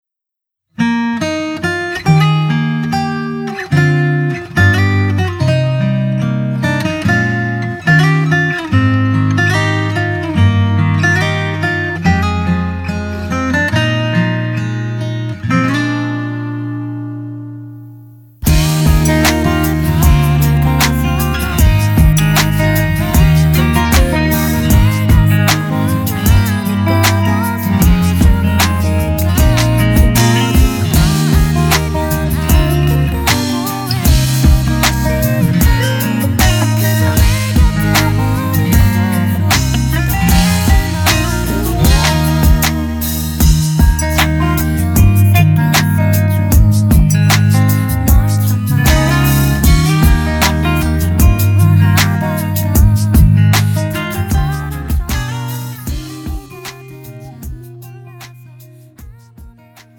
음정 원키
장르 가요 구분
가사 목소리 10프로 포함된 음원입니다